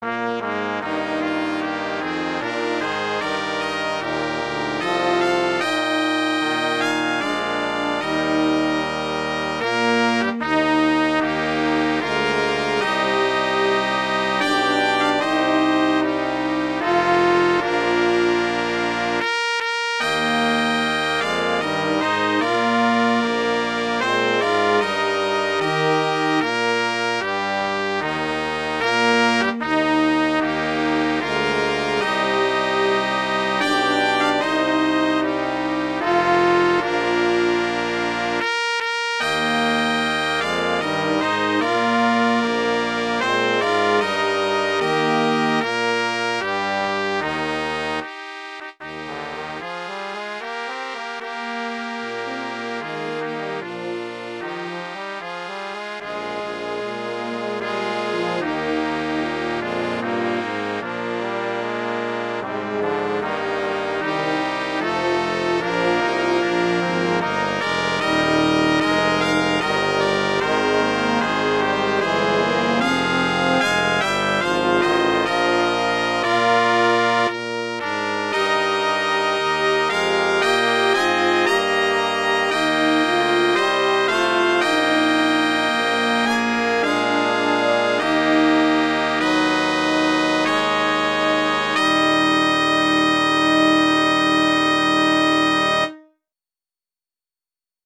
arrangements for brass quintet